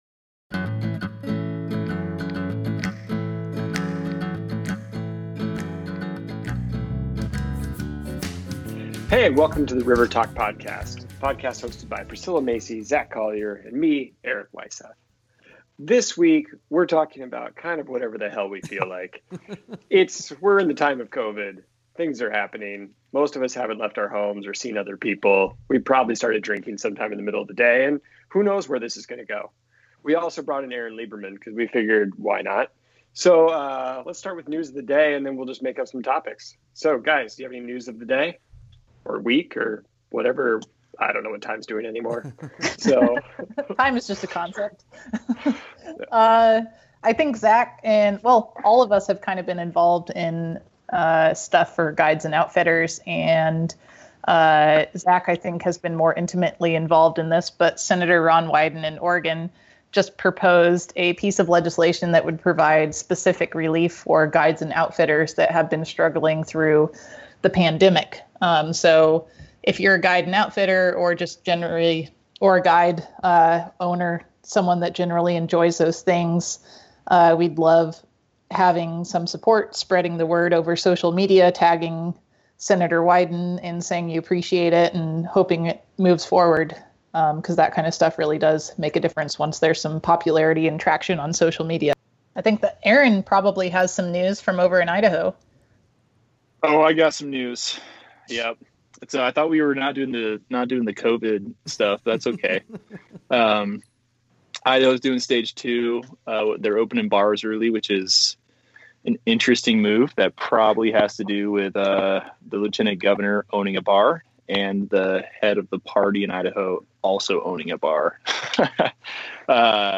This episode is a free for all debate about some of our favorite debate topics.